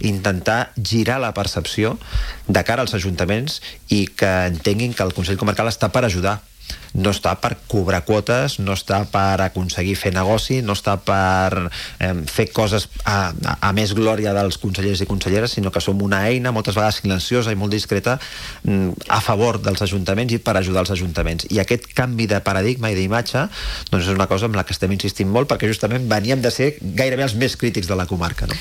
El nou president del Consell Comarcal del Maresme, Rafa Navarro (JUNTS), ha passat pels estudis de Ràdio TV per analitzar els principals reptes de la comarca.